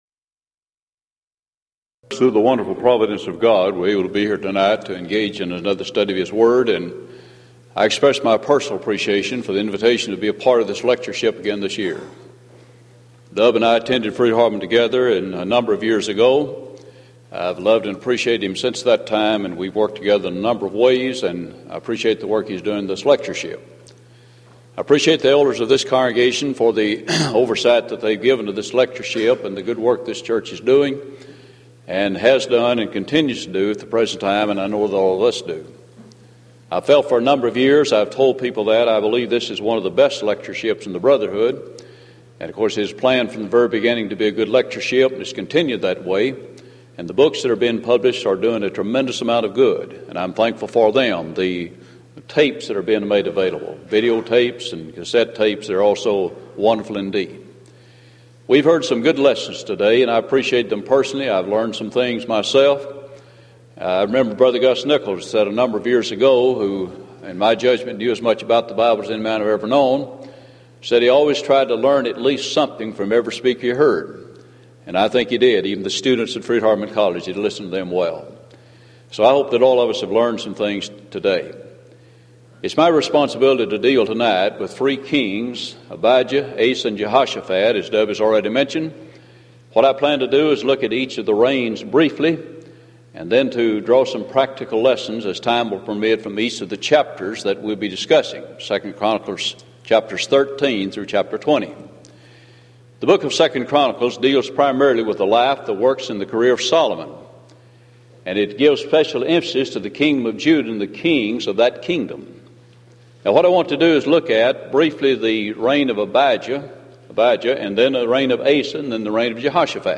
Event: 1993 Denton Lectures
lecture